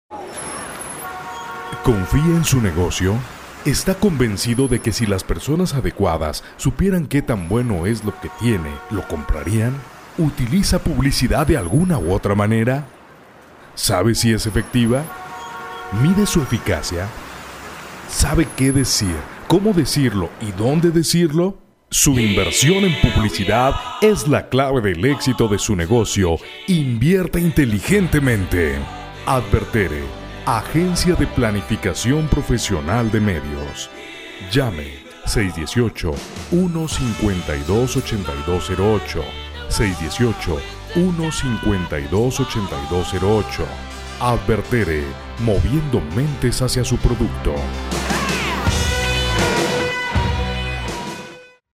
locutor versatil tanto para voz comercial, institucional, y doblaje
locutor profesional perfecto español
kastilisch
Sprechprobe: Industrie (Muttersprache):